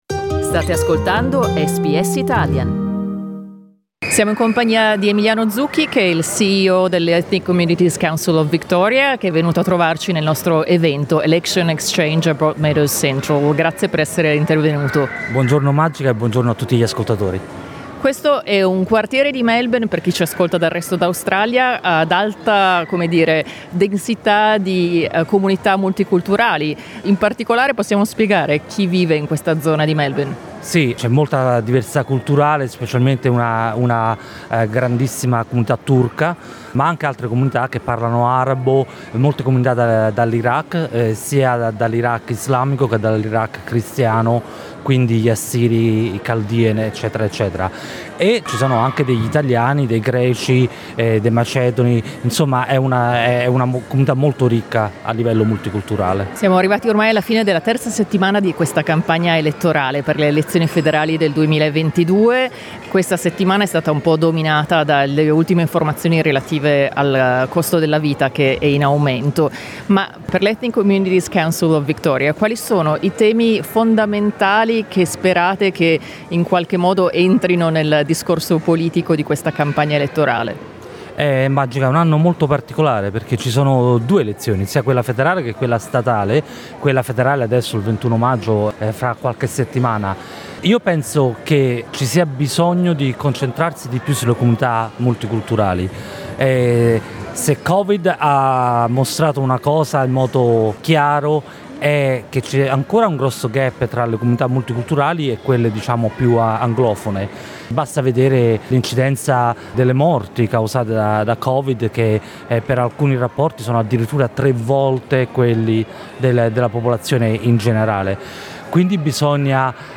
"Election Exchange" è un ciclo di incontri organizzato da SBS radio in varie città australiane, per incontrare candidate/i, esponenti delle organizzazioni comunitarie ed elettori/elettrici.
Sabato 30 aprile SBS Radio ha tenuto un incontro con candidate/i, esponenti delle comunità etniche e comuni elettori ed elettrici a Broadmeadows, quartiere nella zona nord di Melbourne.